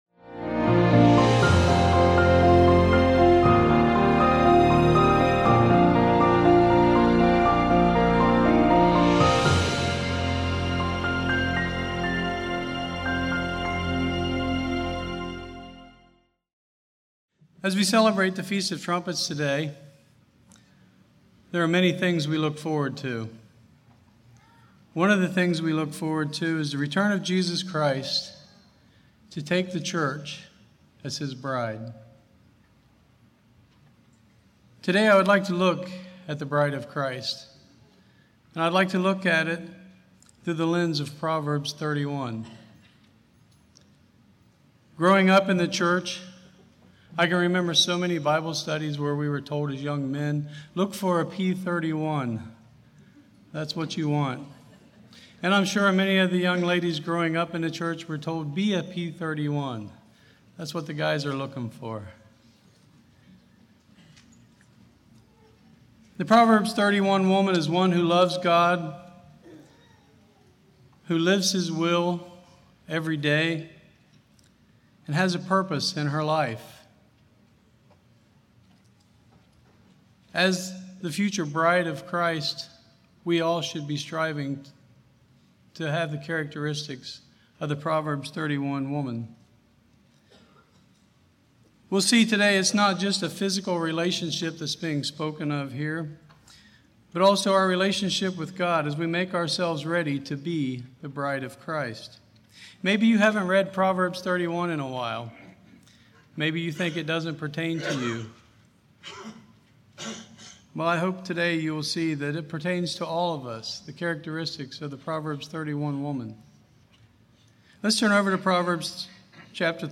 This message was given at the afternoon service during the Feast of Trumpets.